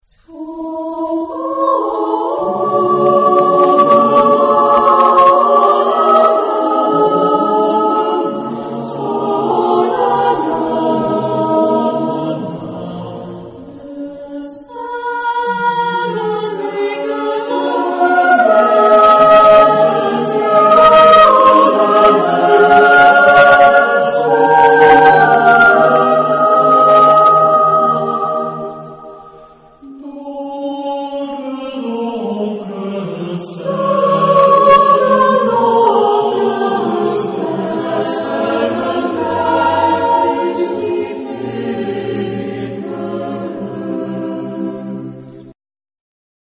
Epoque: 20th century
Type of Choir: SATB  (4 mixed voices )